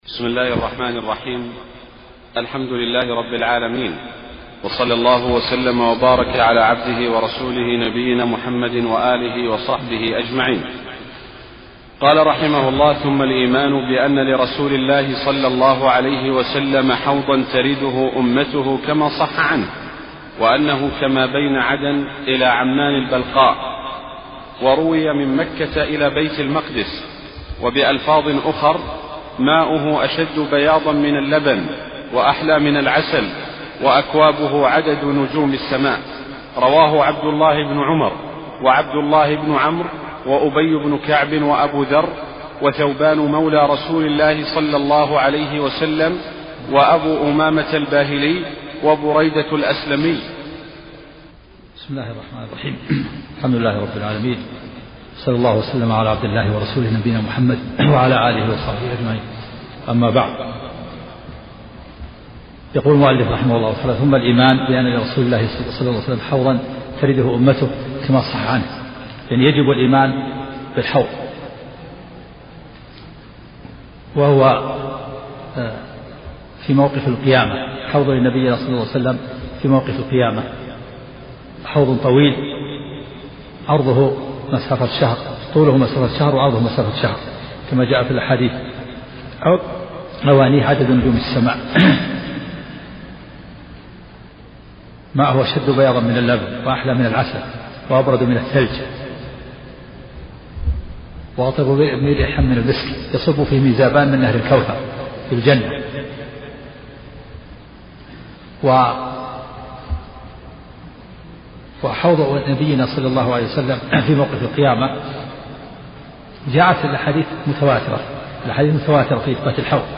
سلسلة محاضرات صوتية، وفيها شرح الشيخ عبد العزيز الراجح